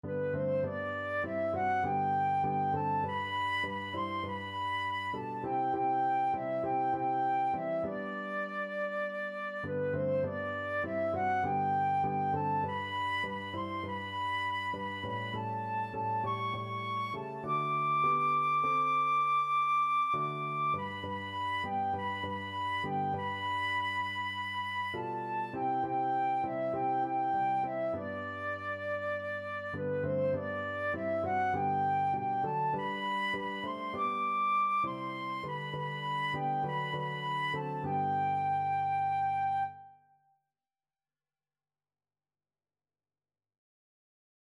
Flute
G major (Sounding Pitch) (View more G major Music for Flute )
4/4 (View more 4/4 Music)